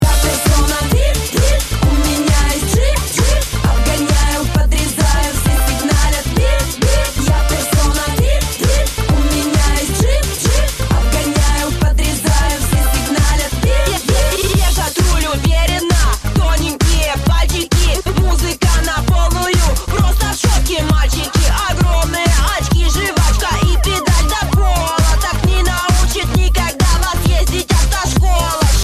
очень стильная и драйвовая